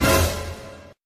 suspense.wav